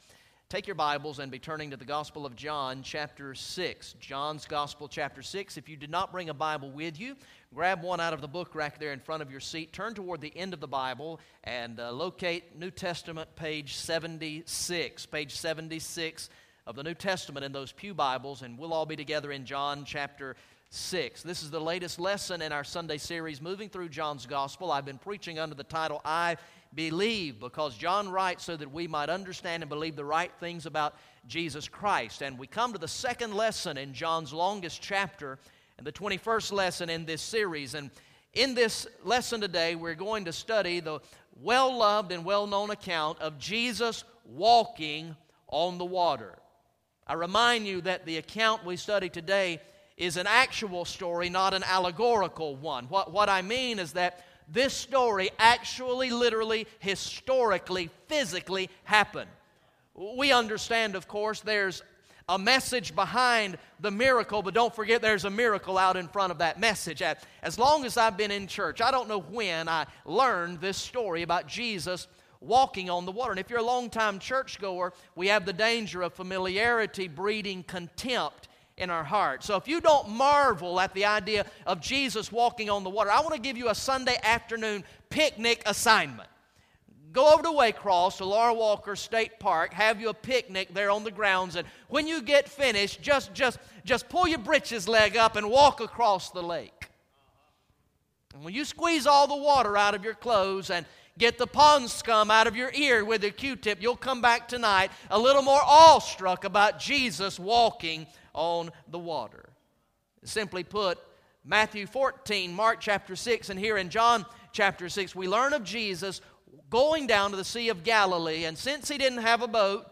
Message #21 from the sermon series through the gospel of John entitled "I Believe" Recorded in the morning worship service on Sunday, October 12, 2014